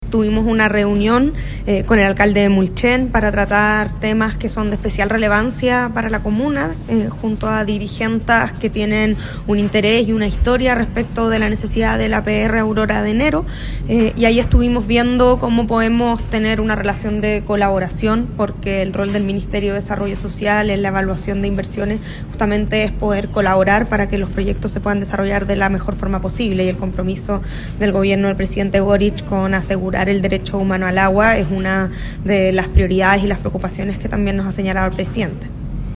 La ministra de Desarrollo Social y Familia, Javiera Toro, indicó que la idea es colaborar en este tipo de proyectos.